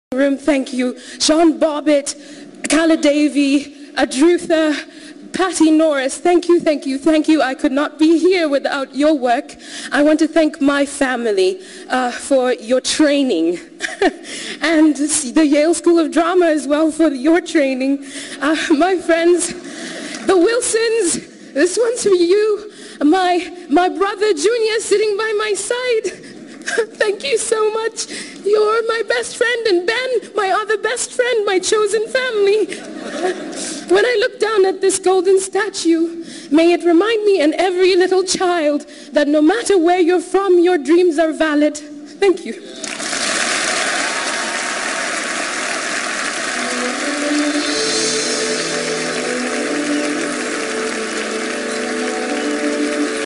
Hear her acceptance speech, award won from movie '12 Years A Slave'.